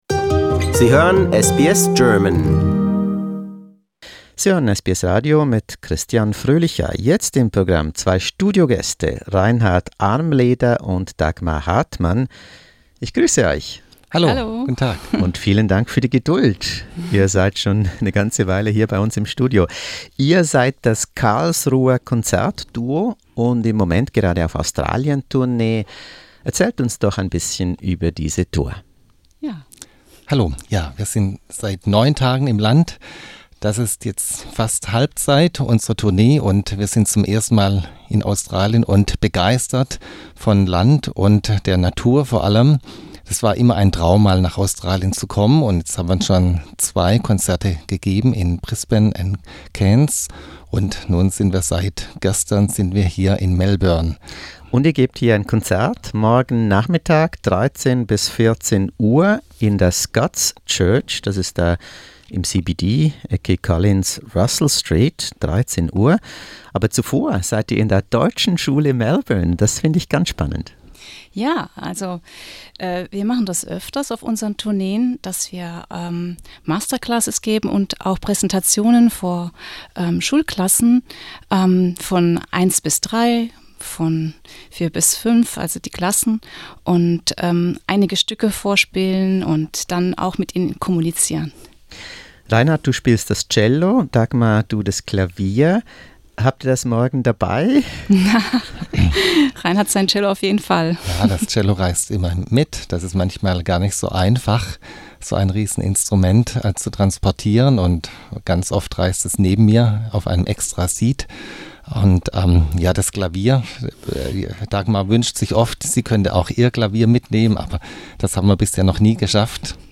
beim Interviewtermin in Melbourne